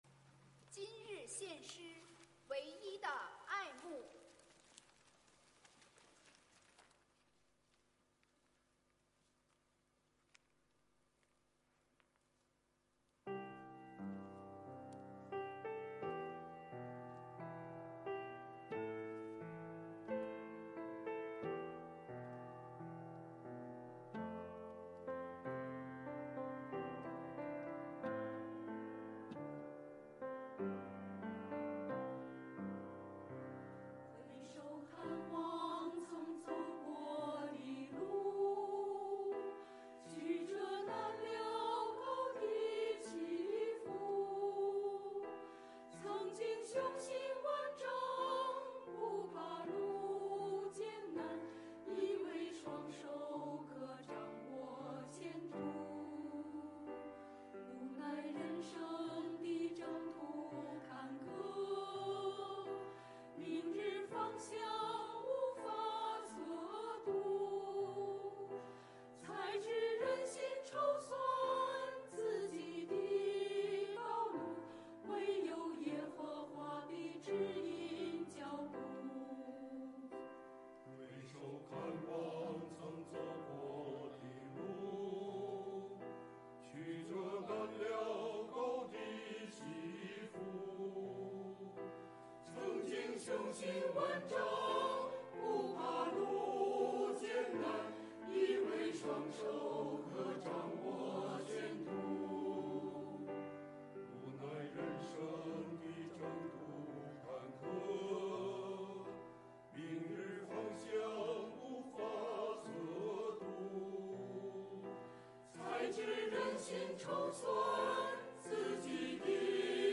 团契名称: 联合诗班 新闻分类: 诗班献诗 音频: 下载证道音频 (如果无法下载请右键点击链接选择"另存为") 视频: 下载此视频 (如果无法下载请右键点击链接选择"另存为")